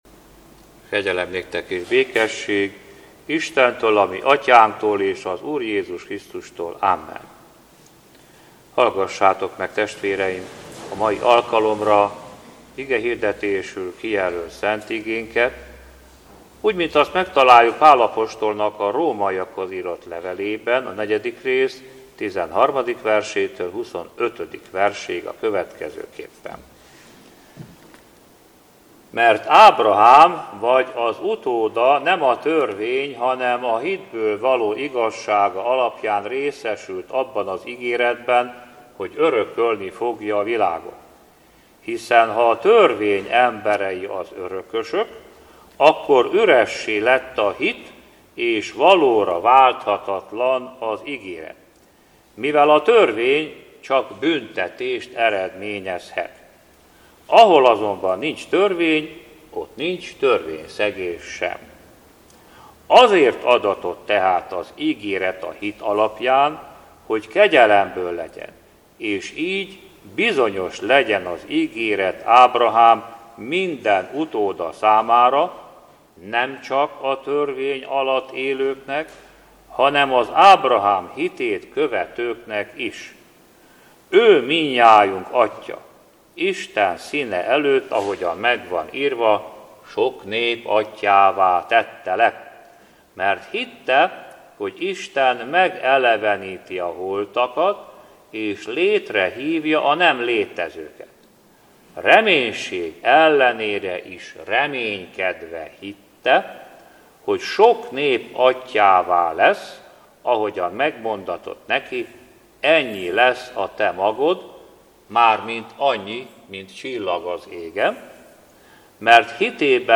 HÚSVÉT 2. NAPJA - Hát nem ezt kellett-e elszenvednie a Krisztusnak, és így megdicsőülnie? (Lk 24,26) - Igét hirdet